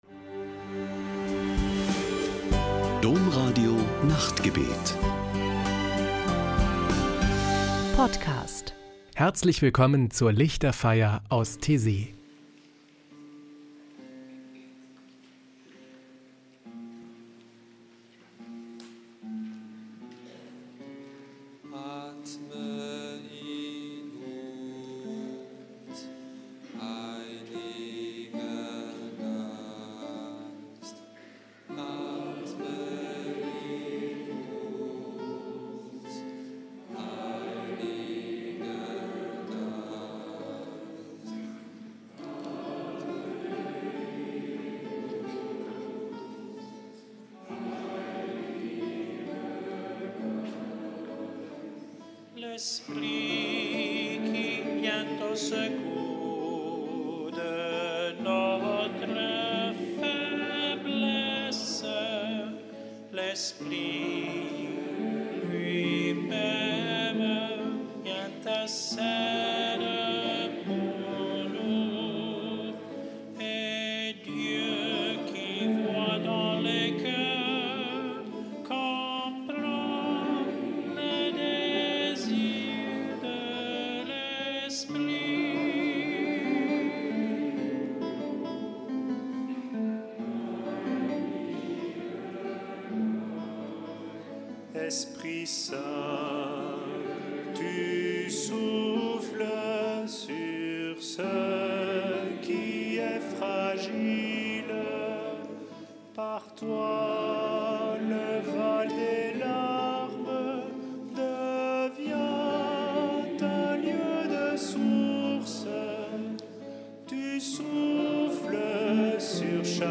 Ein Höhepunkt jede Woche ist am Samstagabend die Lichterfeier mit meditativen Gesängen und Gebeten.